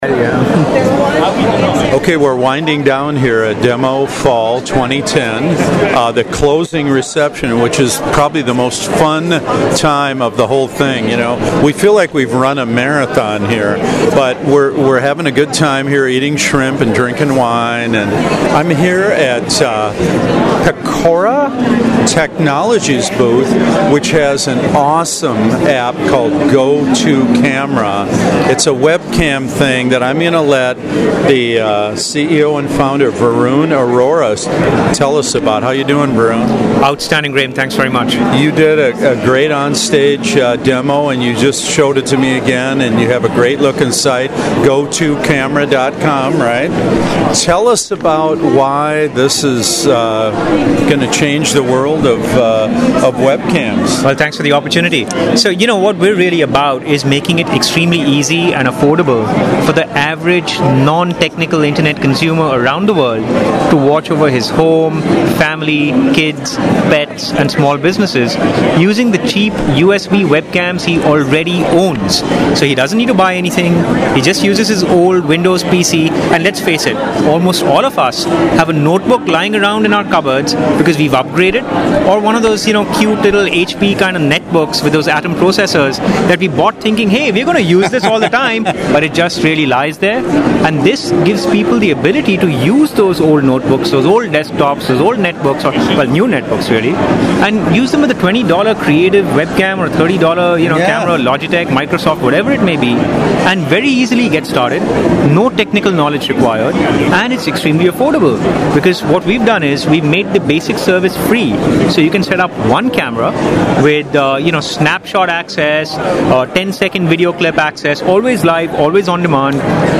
My last interview at this DEMOfall was one I vowed to do the moment I saw this company's pitch on stage.